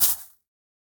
Minecraft Version Minecraft Version snapshot Latest Release | Latest Snapshot snapshot / assets / minecraft / sounds / block / nether_sprouts / step1.ogg Compare With Compare With Latest Release | Latest Snapshot
step1.ogg